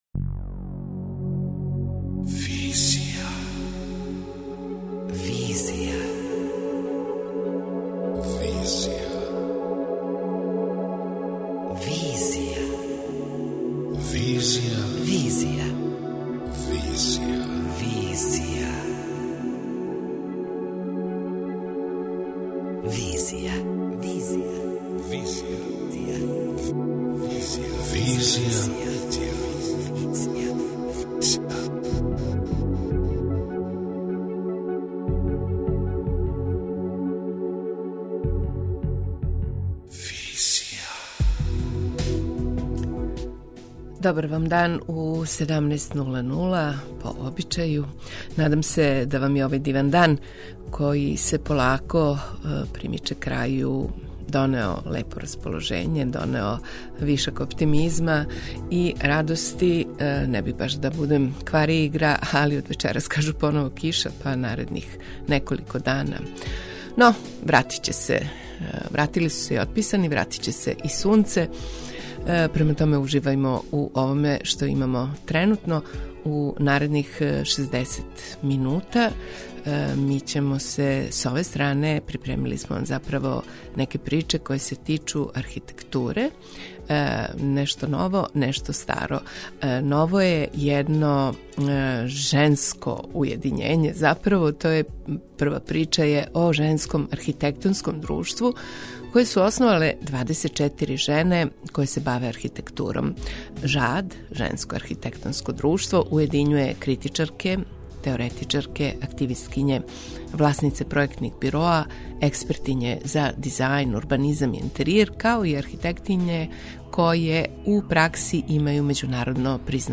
преузми : 26.79 MB Визија Autor: Београд 202 Социо-културолошки магазин, који прати савремене друштвене феномене.